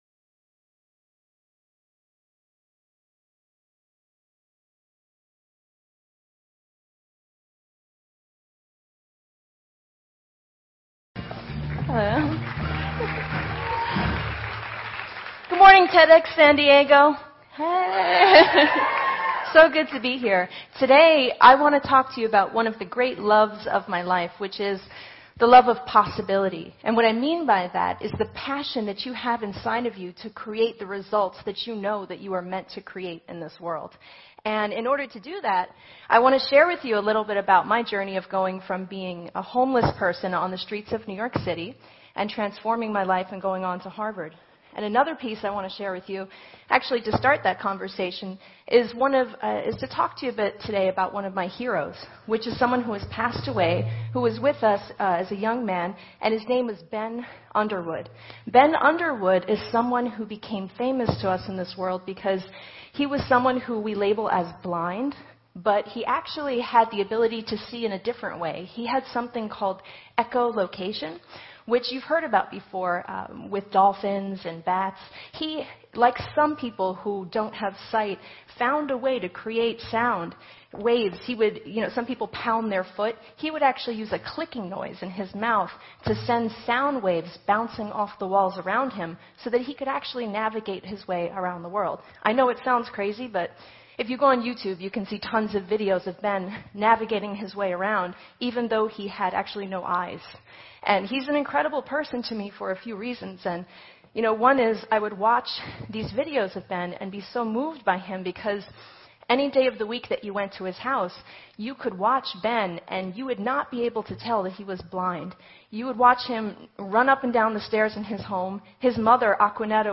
Here is the full text of Liz Murray’s talk: For the Love of Possibility at TEDxYouth conference.